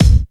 Old School Thumpy Steel Kick Drum C Key 281.wav
Royality free kickdrum sound tuned to the C note. Loudest frequency: 414Hz
old-school-thumpy-steel-kick-drum-c-key-281-iLr.ogg